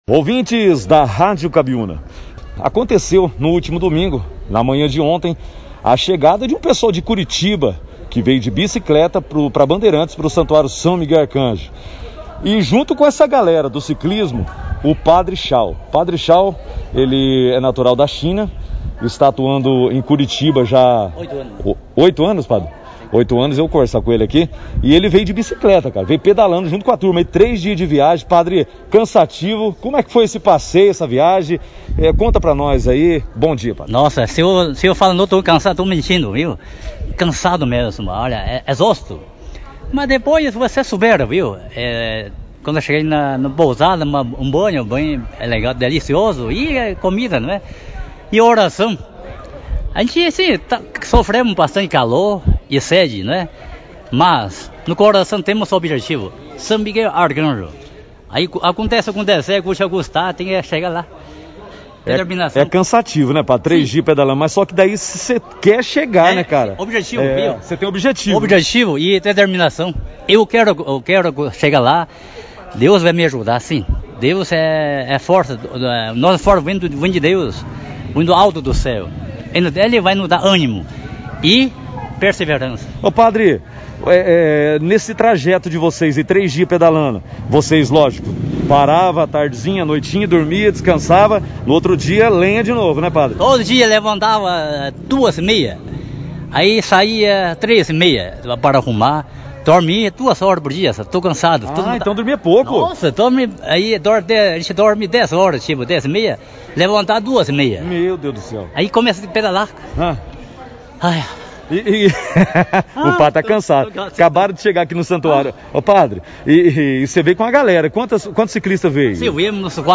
este feito foi destaque da 1ª edição do Jornal Operação Cidade